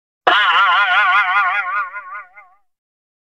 Boing Eff Sound Button - Free Download & Play